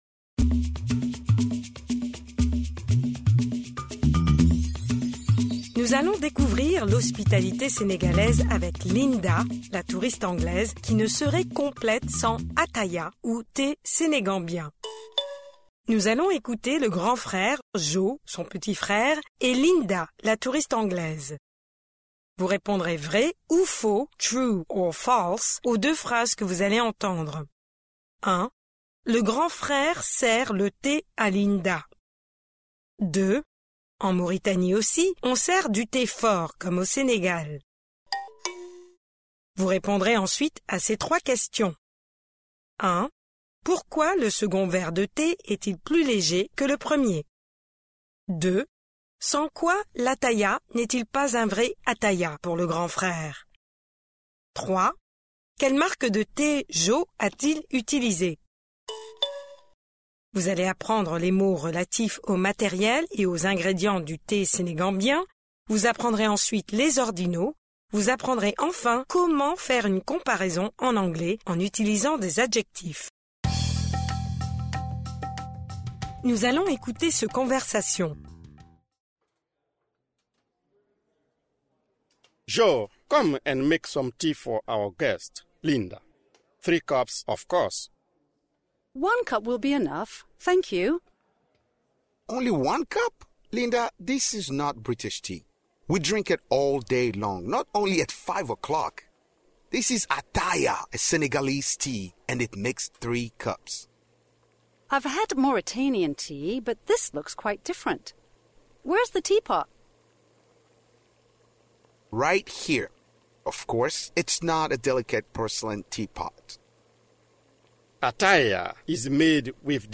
Nous allons découvrir l’hospitalité Sénégalaise avec Linda, la touriste Anglaise, qui ne serait complète sans Attaya ou thé Sénégambien. Pre-listening: Nous allons écouter le grand-frère, Jo son petit-frère, et Linda, la touriste Anglaise.